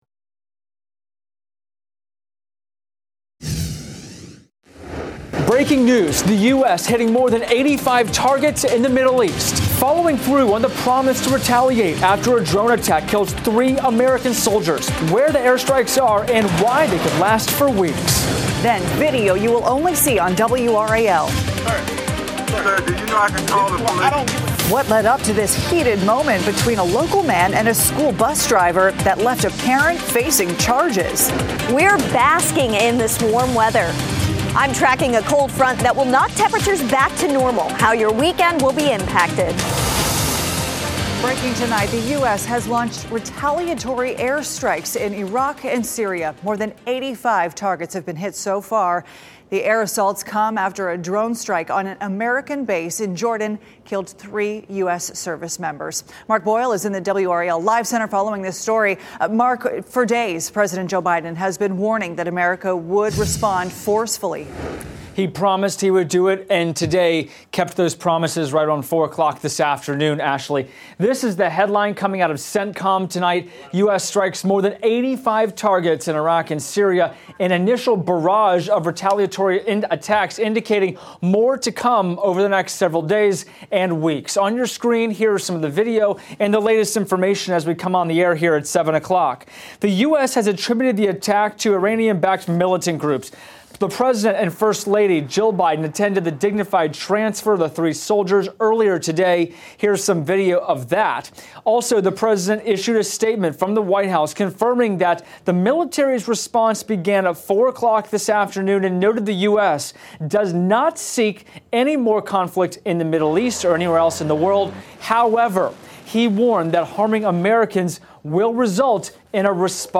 WRAL Newscasts 7PM News on WRAL - Friday, February 2, 2024 Feb 03 2024 | 00:21:55 1x 00:00 / 00:21:55 Subscribe Share RSS Feed Share Link Embed